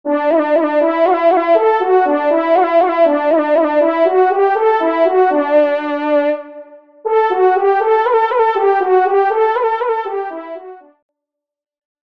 Genre : Fanfare d’Animaux
Pupitre de Chant